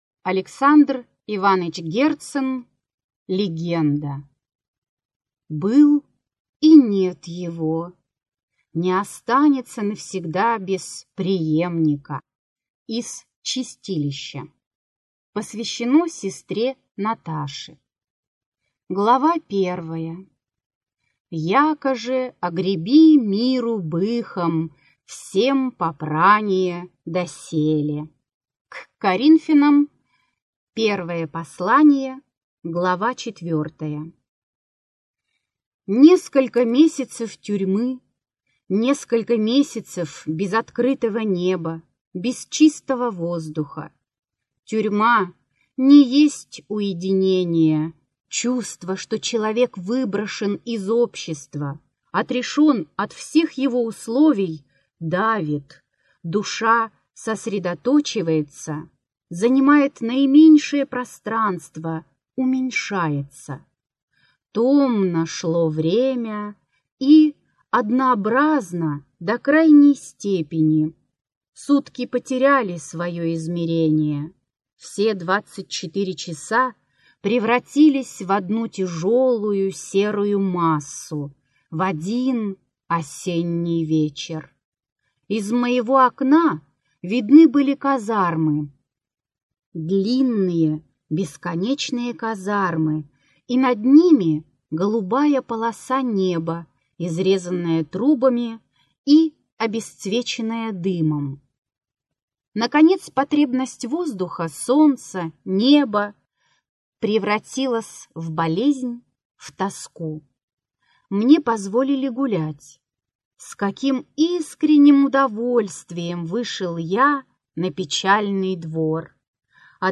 Аудиокнига Легенда. Сорока-воровка | Библиотека аудиокниг